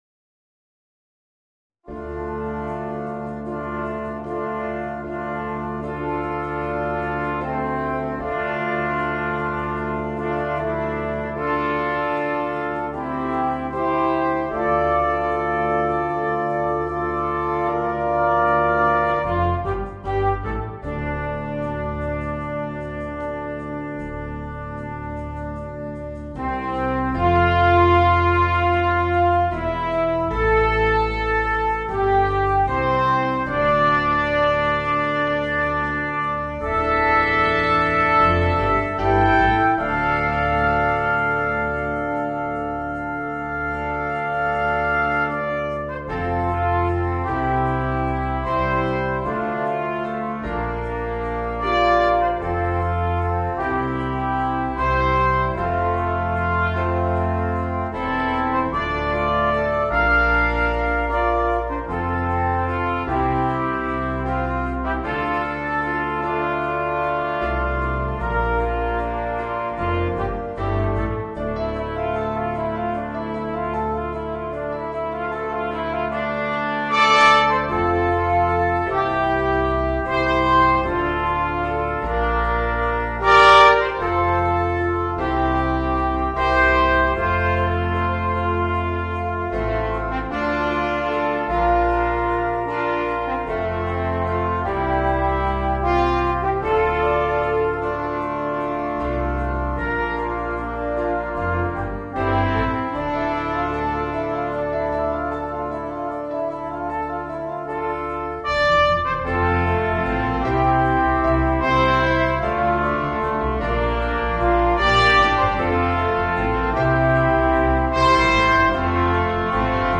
Voicing: 4 - Part Ensemble